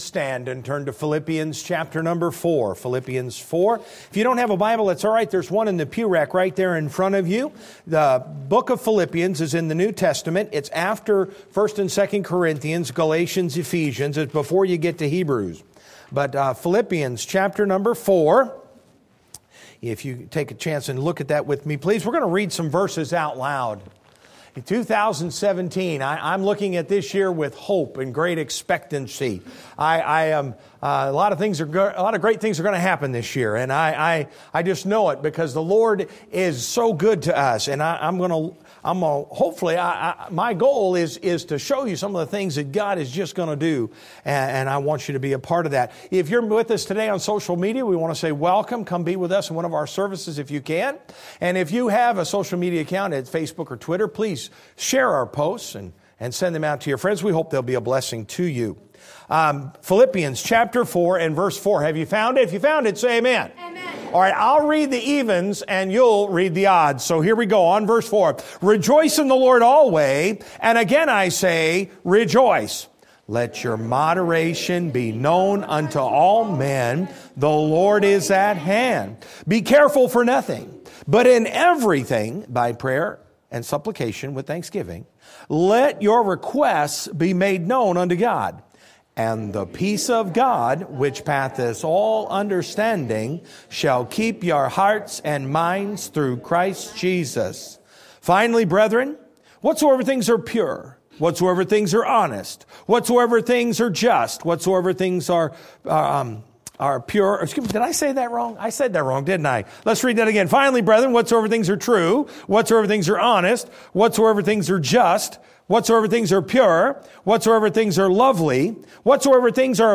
Sermon Audio :: First Baptist Church of Kingstowne